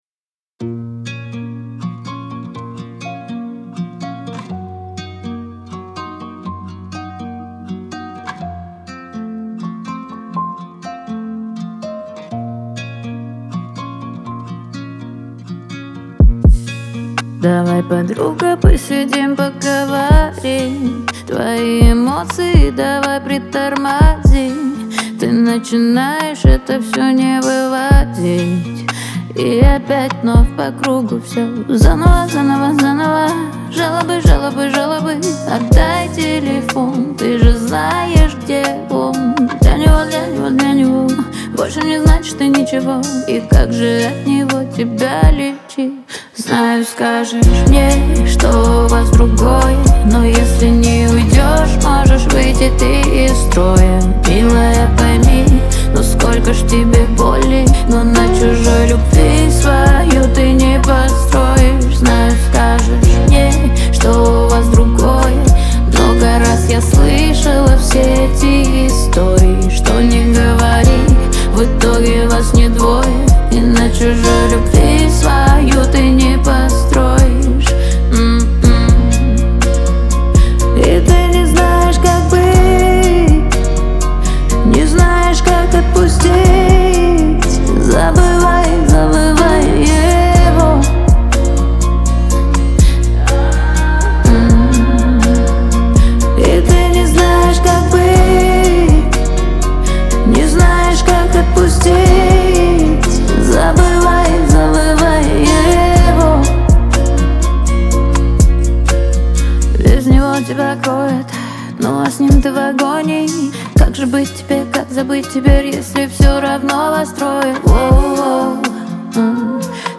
• Категория: Русские песни